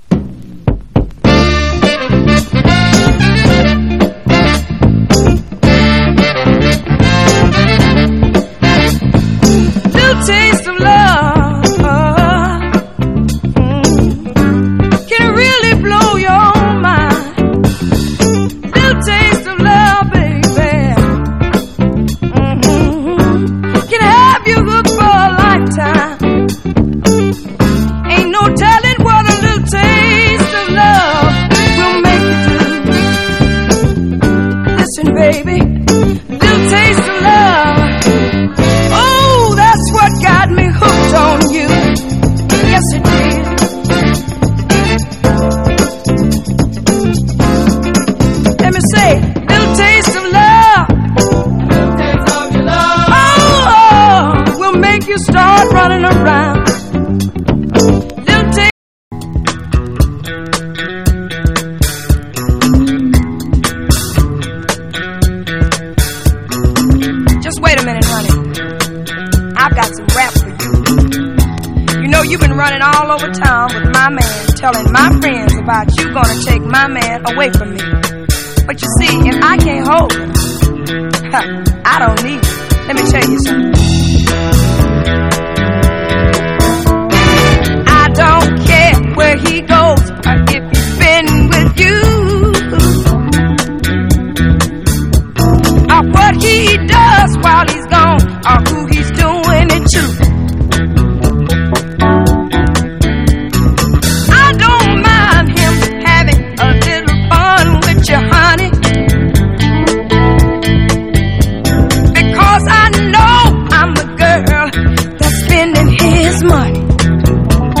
ROCK / 70'S / MODERN POP (UK)
通してほのぼのと心地よいナンバーばかりが詰まった作品です。